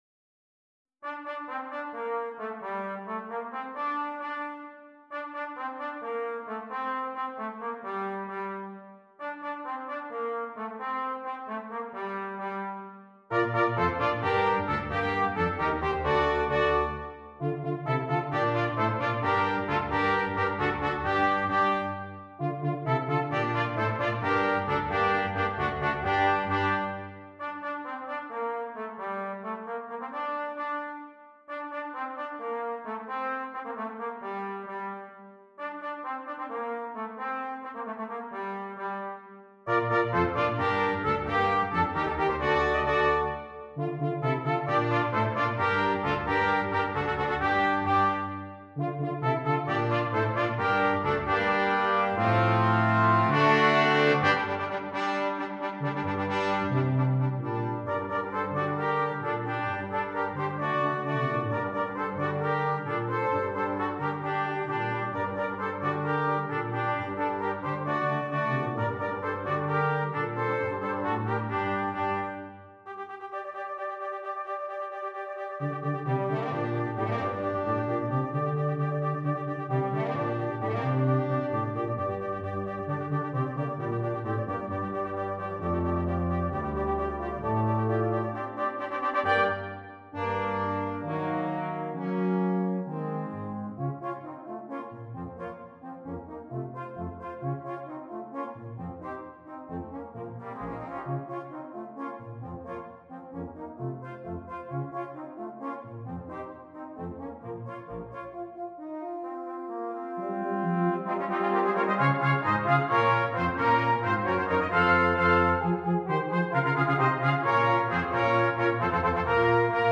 Brass Quintet
Traditional Spanish Carol